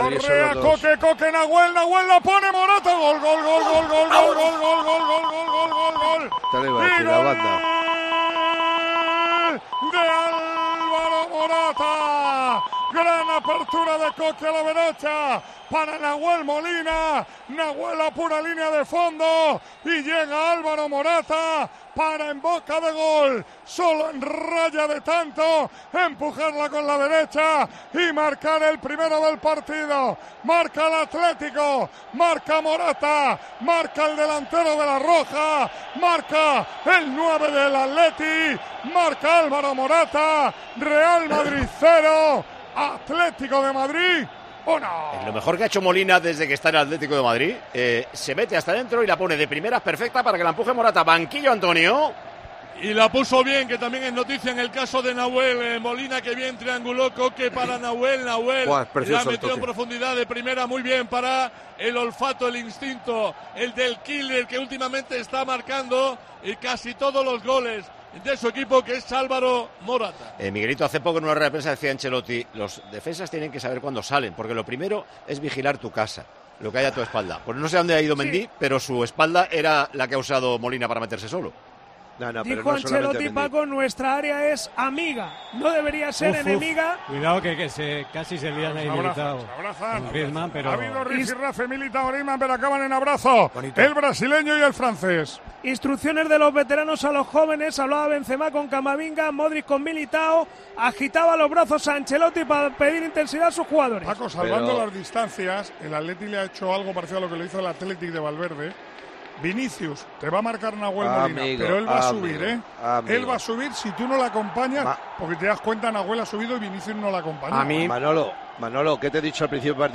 Tiempo de Juego, en el Santiago Bernabéu.